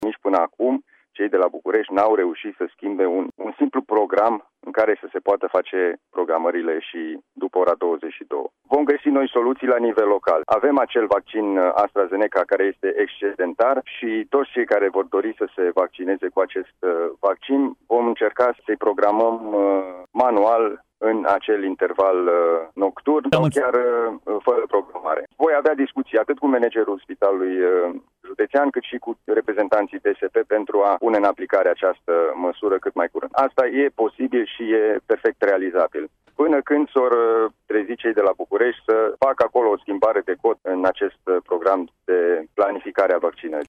În direct, la Radio Timișoara, Alin Nica a acuzat autoritățile centrale că, după o lună de la deschiderea acestui centru, încă nu se pot face programări pe platformă, pe timpul nopții.